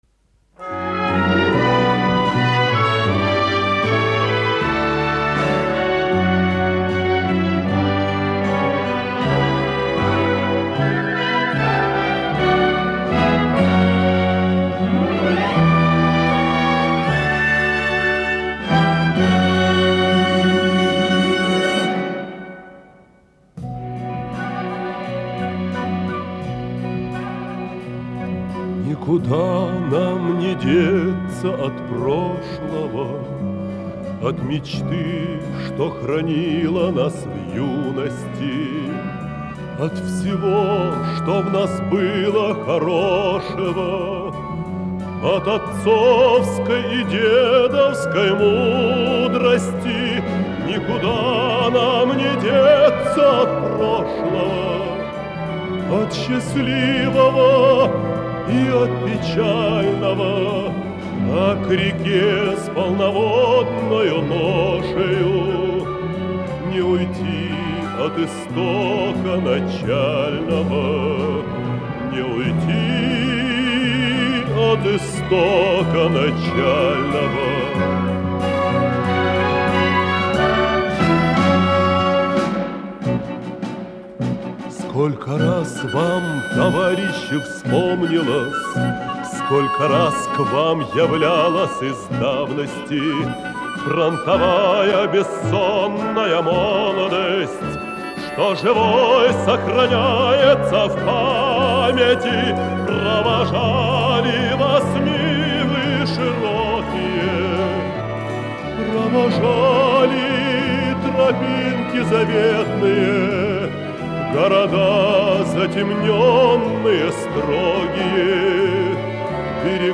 Источник фонотека Пензенского Дома радио